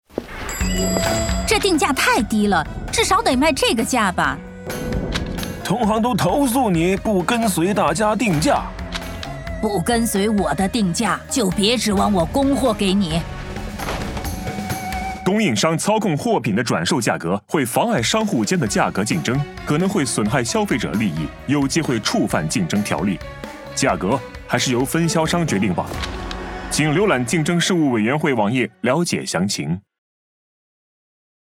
电视及电台宣传